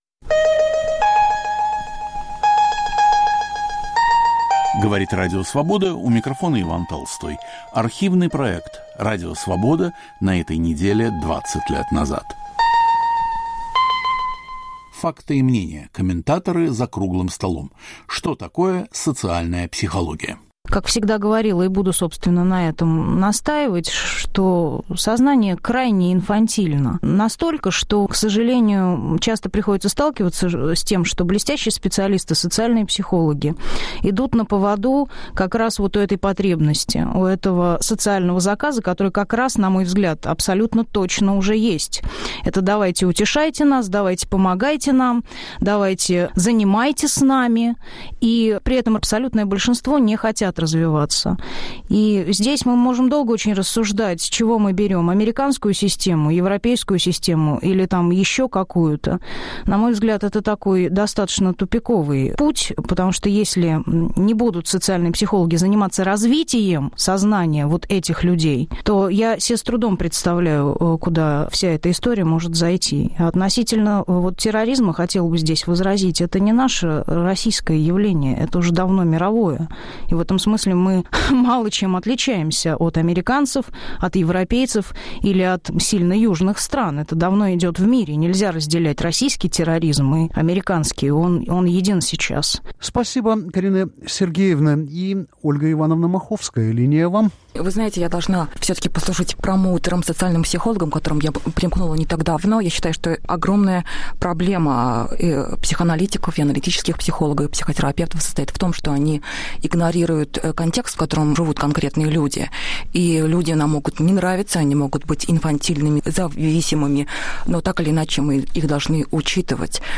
Об инфантилизме и необходимости развивать сознание. Участвуют психологи